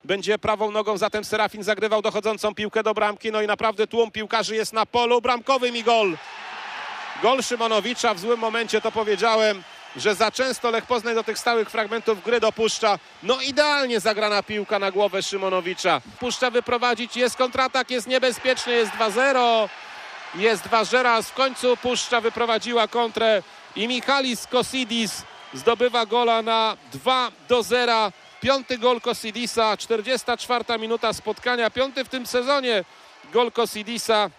zcx83byka5fd5q1_gole_puszcza_lech.mp3